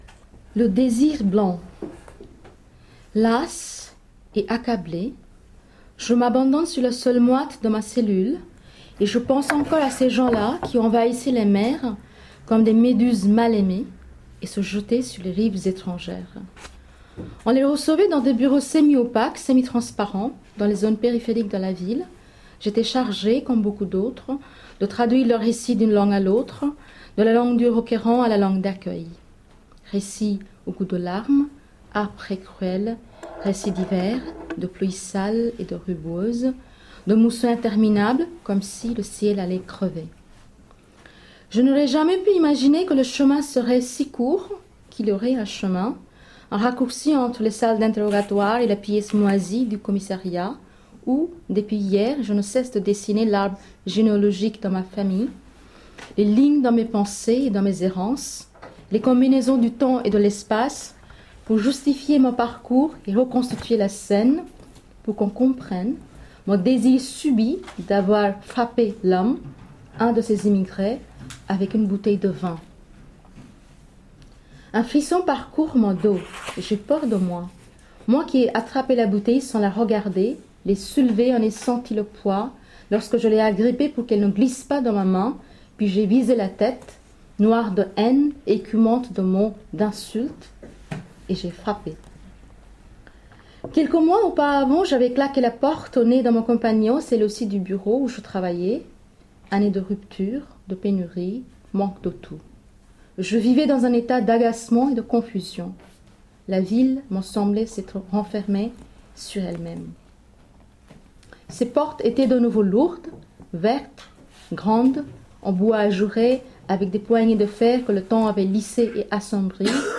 Ein kurzer Auszug aus der Lesung vom 3.9.2015 im Buchladen Osterstraße